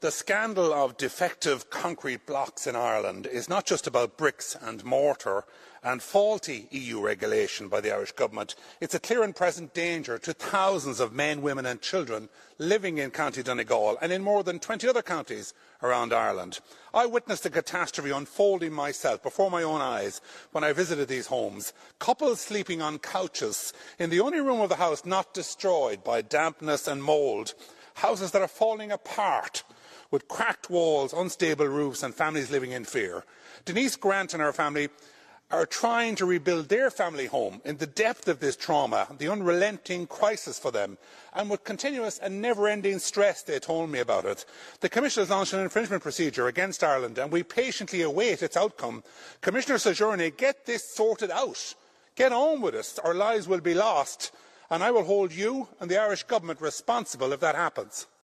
Speaking in Strasbourg, Mr Mullooly says those in power will be held to account unless action is taken: